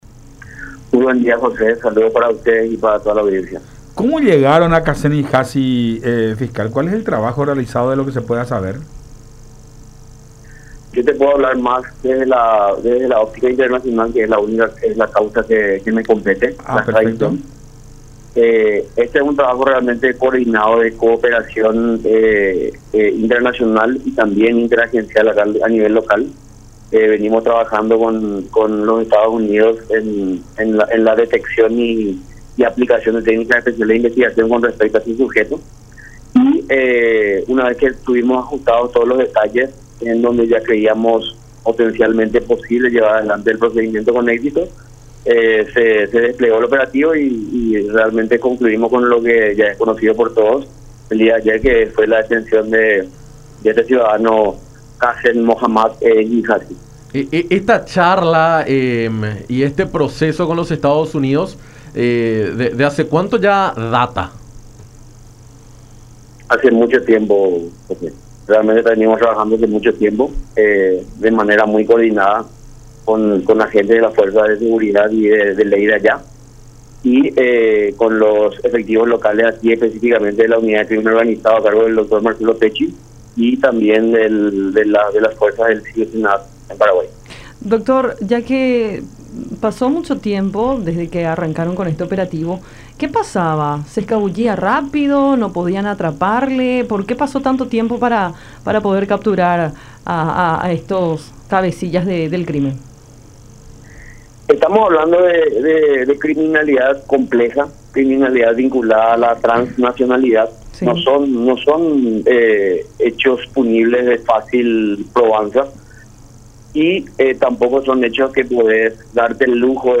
Es un trabajo complejo y quizás parezca que pasó mucho tiempo, pero se hacen diferentes acciones que luego cierran con una posible detención”, expuso Manuel Doldán, fiscal de Asuntos Internacionales, en conversación con Enfoque 800 por La Unión. https